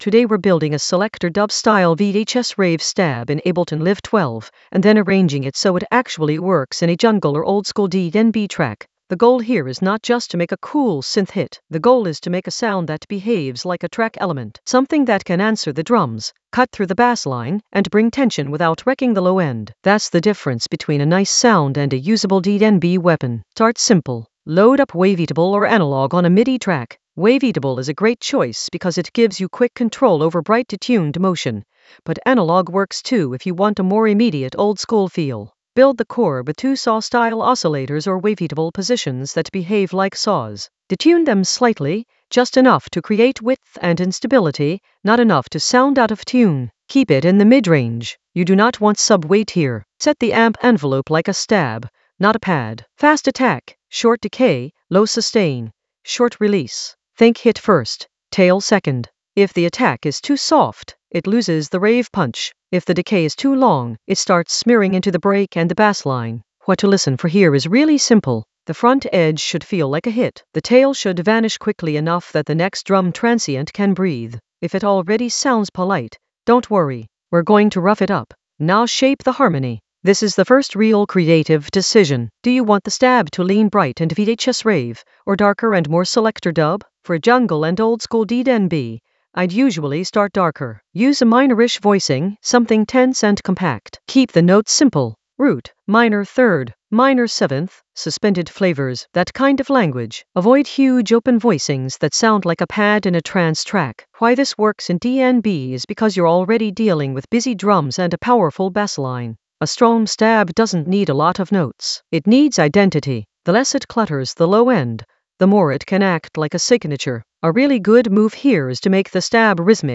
An AI-generated intermediate Ableton lesson focused on Selector Dub a VHS-rave stab: design and arrange in Ableton Live 12 for jungle oldskool DnB vibes in the Sound Design area of drum and bass production.
Narrated lesson audio
The voice track includes the tutorial plus extra teacher commentary.